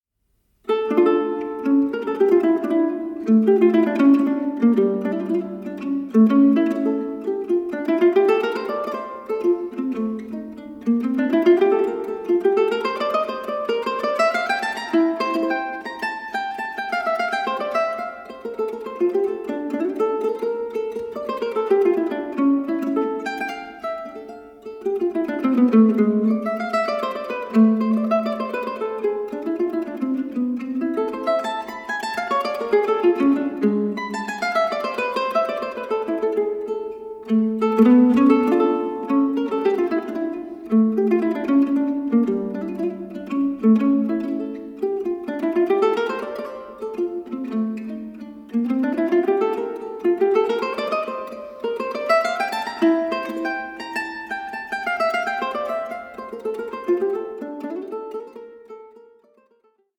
FEATURING MANDOLINS, MANDOLA, LUTE AND BAĞLAMA